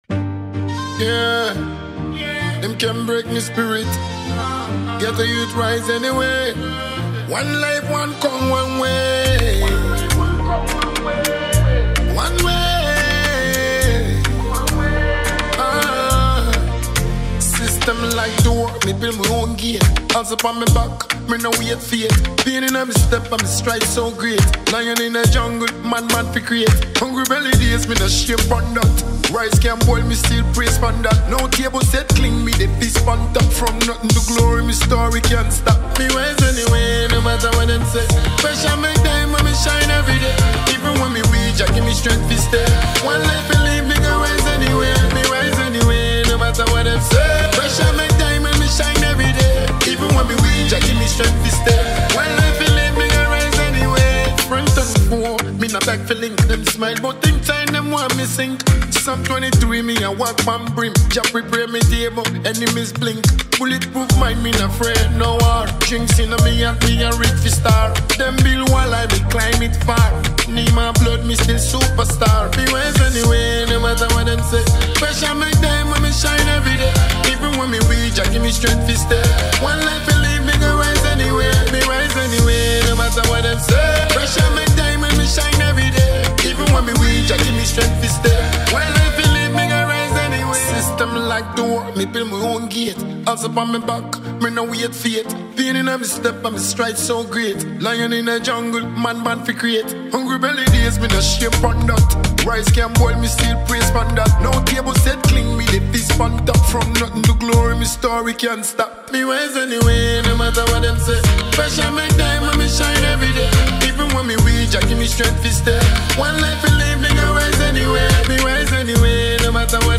inspiring and emotionally charged single